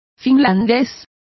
Complete with pronunciation of the translation of finnish.